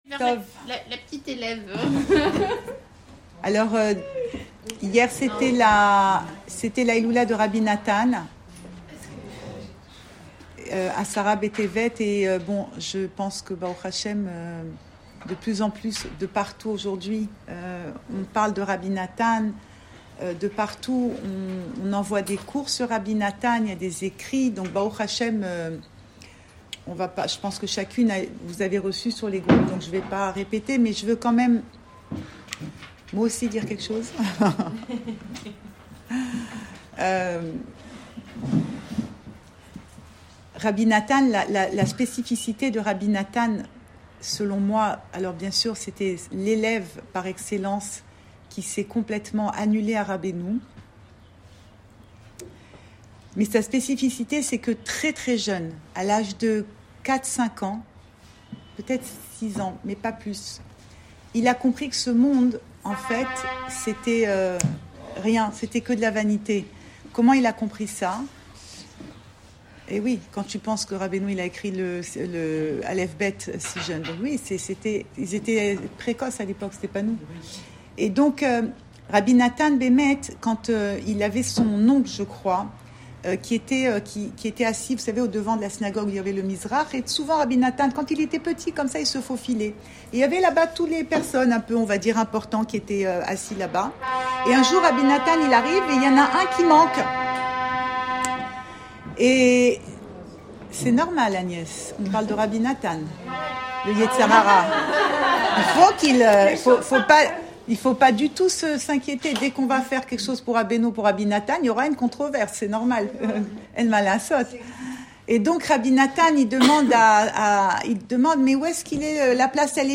Cours audio
Enregistré à Tel Aviv